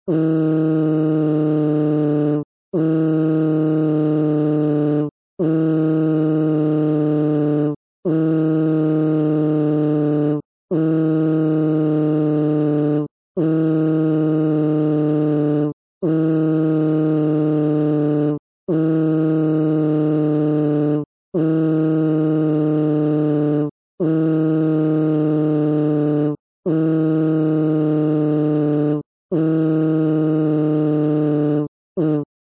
Play, download and share noooo (kinda weird) original sound button!!!!